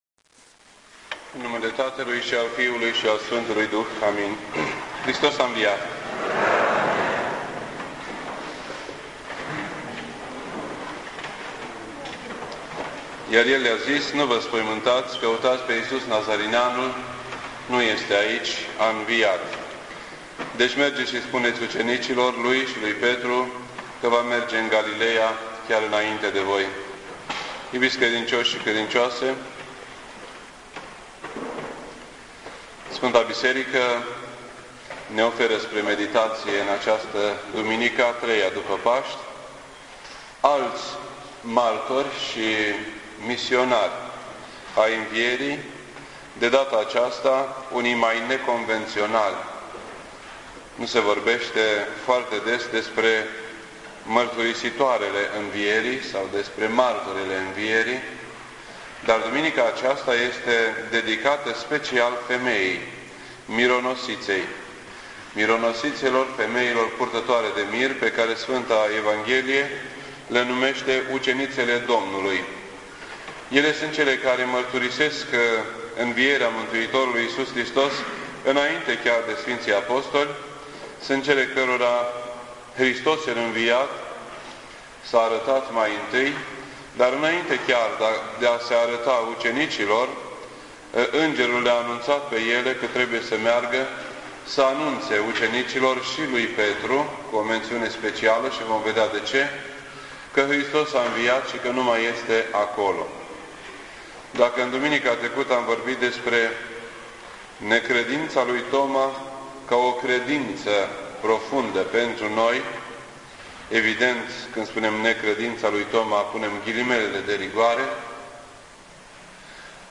This entry was posted on Sunday, May 11th, 2008 at 9:43 AM and is filed under Predici ortodoxe in format audio.